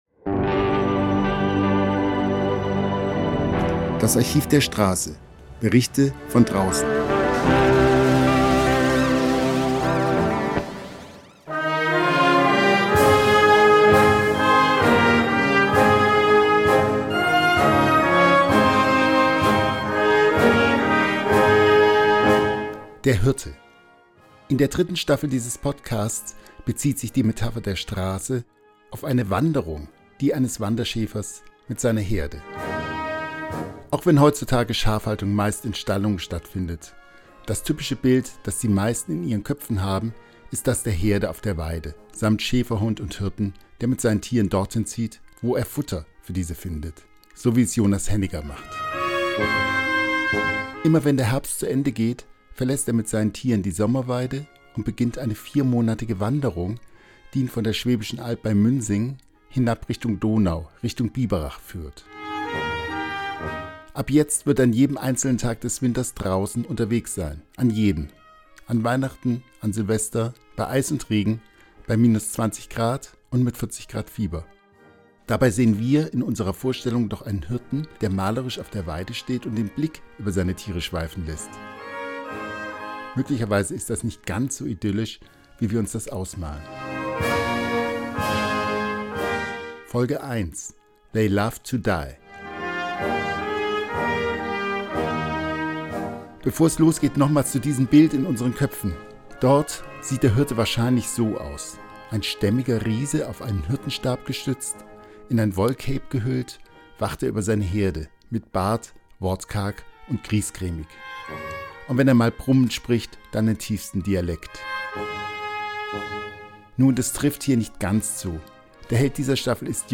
Musik: Bad Uracher Schäfermusik Mehr